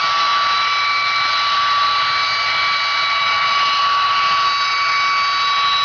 whine-h.wav